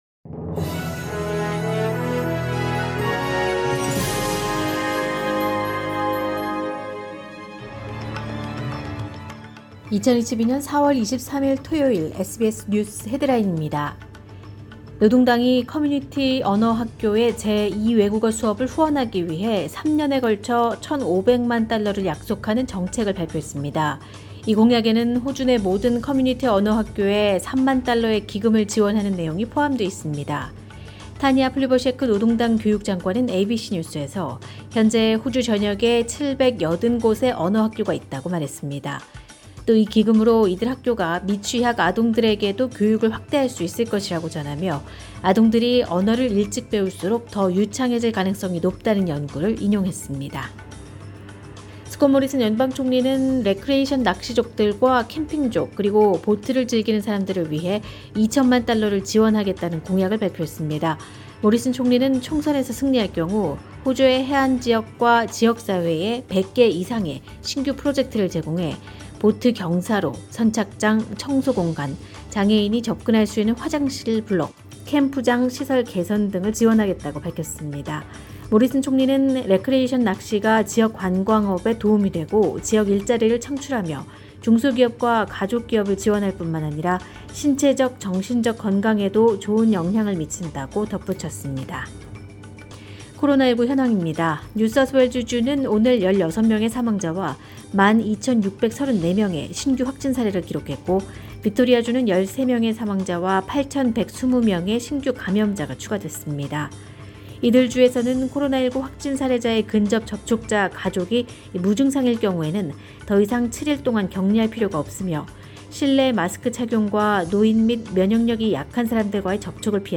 2022년 4월 23일 토요일 SBS 한국어 간추린 주요 뉴스입니다.